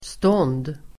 Uttal: [stån:d]